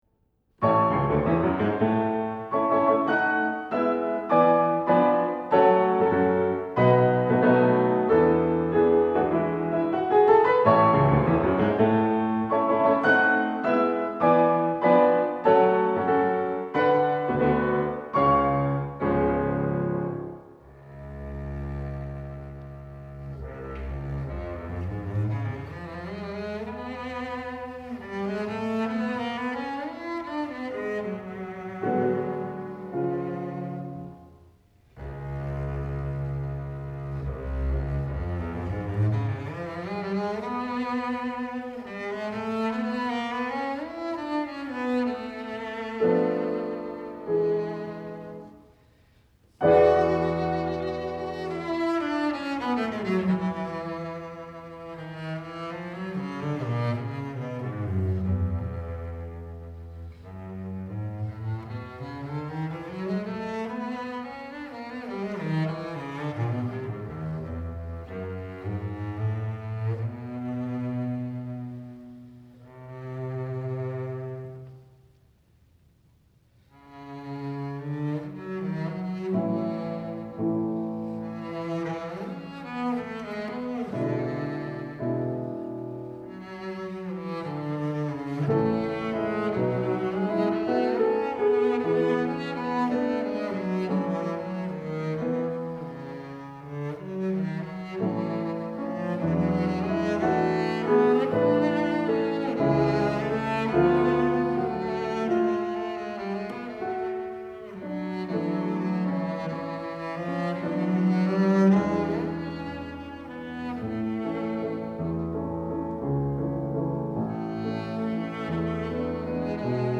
Contrabajo
Piano
Clásica